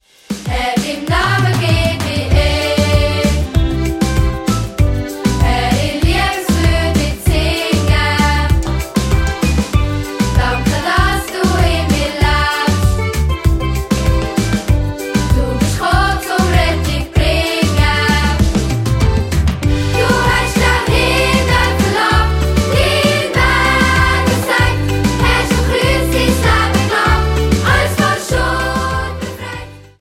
Mundartworship für Kids und Preetens